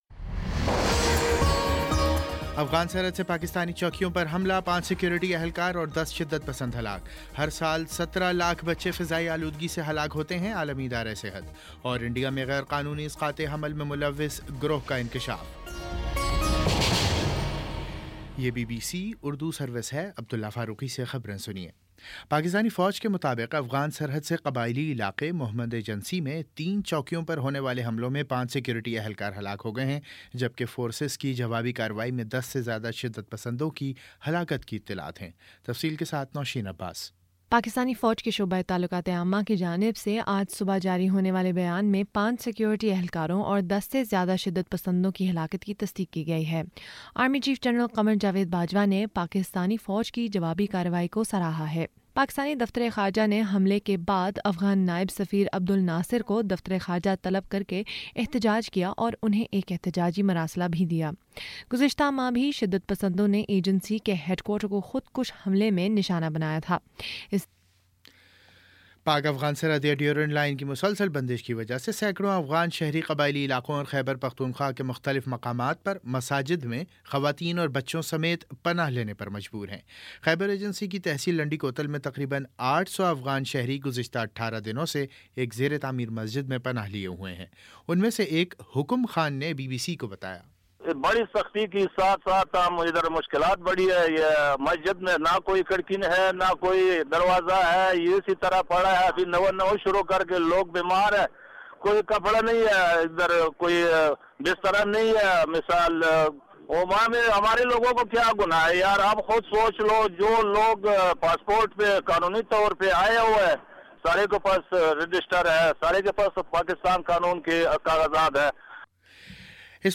مارچ 06 : شام سات بجے کا نیوز بُلیٹن